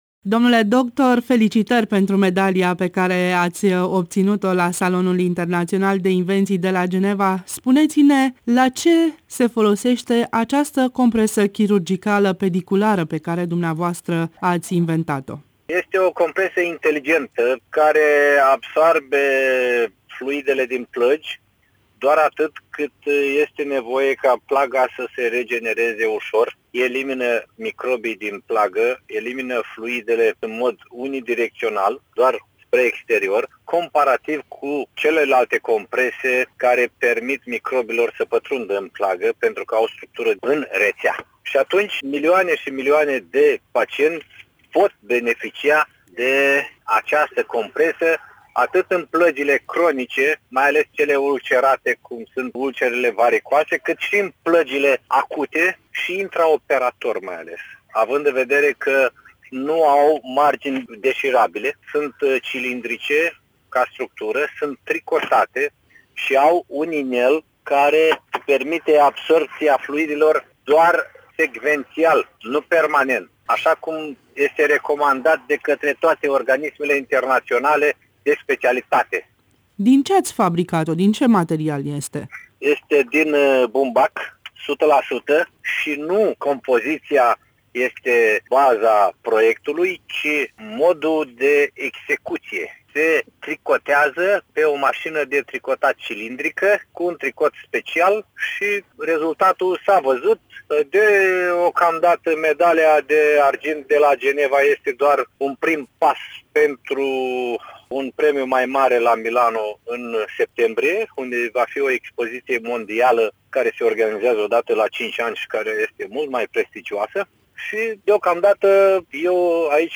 (INTERVIU)